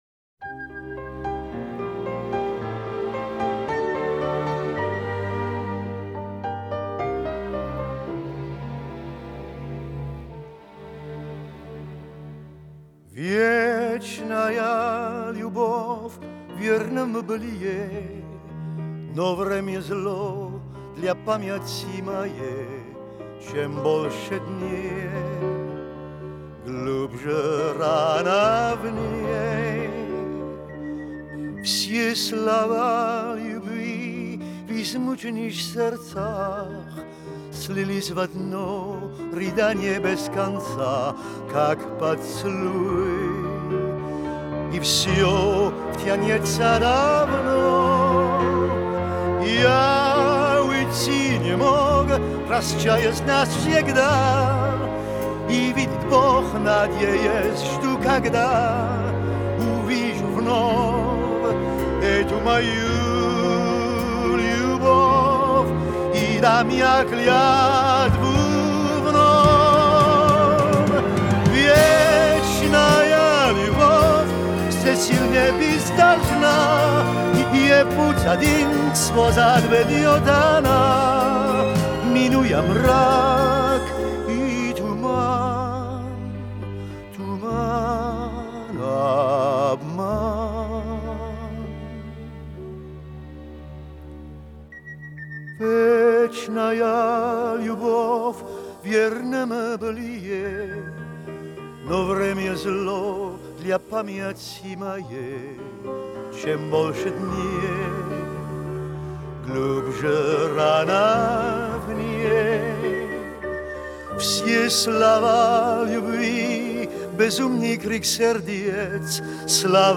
на русском, но с французским акцентом